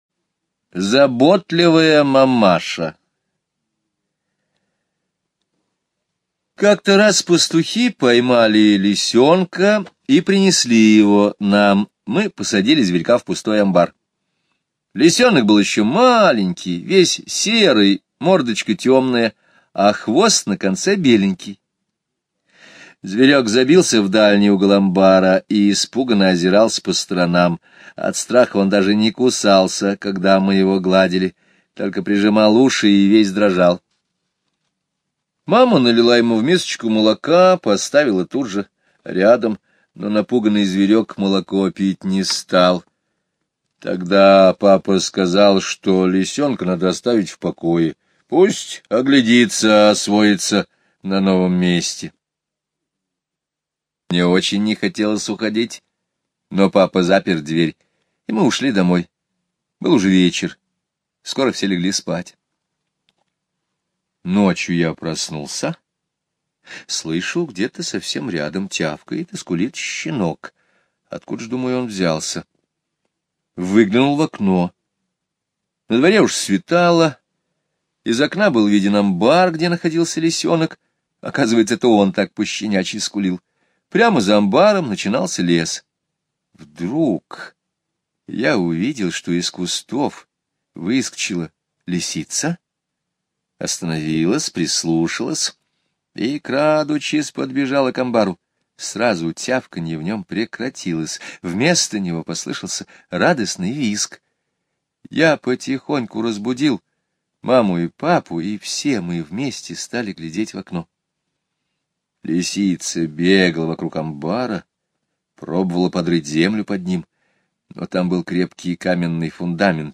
Слушайте Заботливая мамаша - аудио рассказ Скребицкого Г. Как-то раз пастухи принесли домой совсем маленького лисенка.